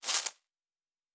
Spark 06.wav